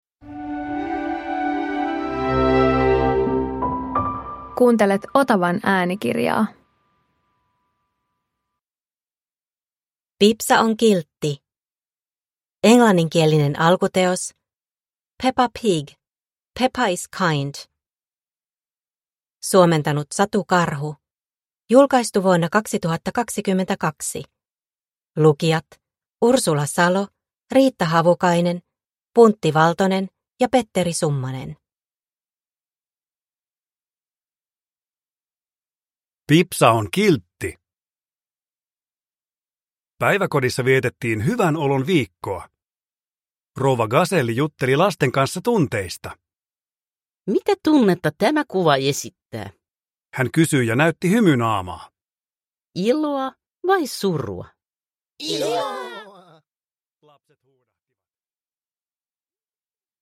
Pipsa on kiltti – Ljudbok – Laddas ner